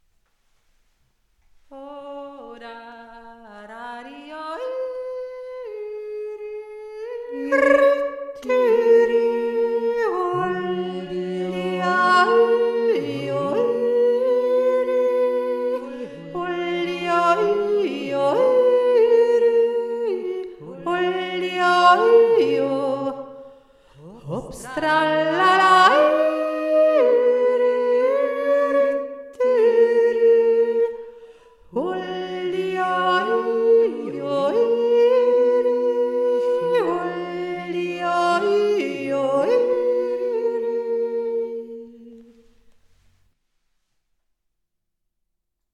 die Hohe